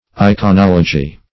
Iconology \I`co*nol"o*gy\, n. [Gr.